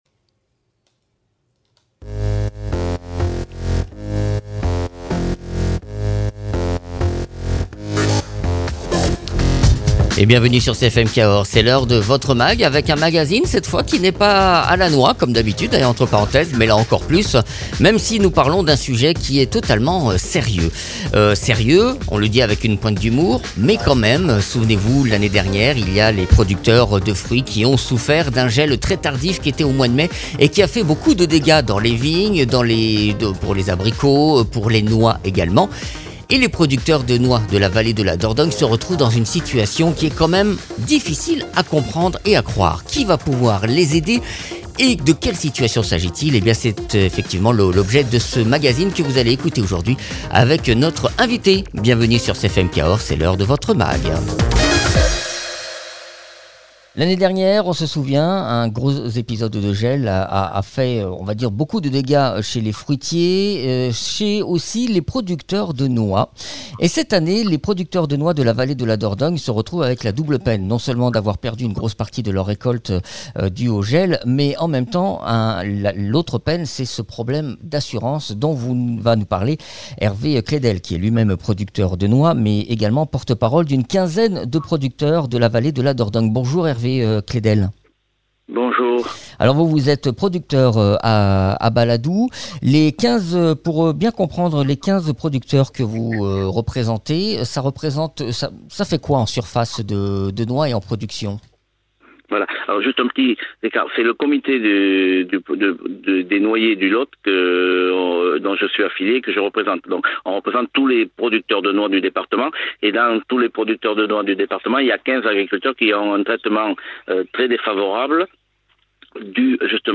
producteur de noix